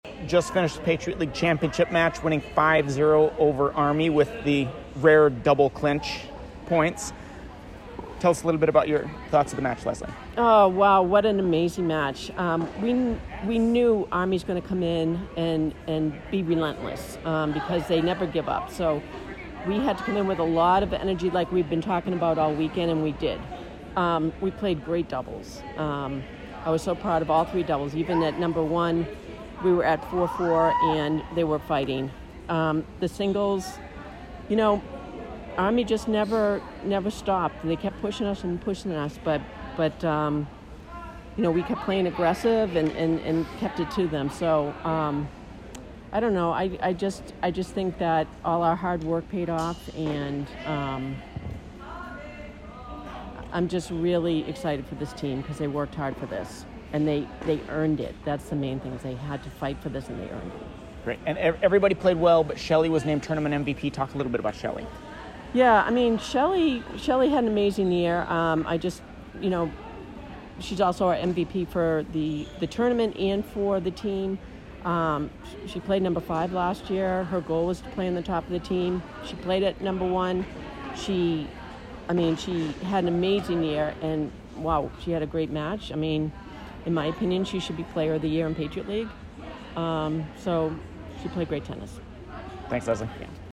Women's Tennis / Army PL Final Postmatch Interview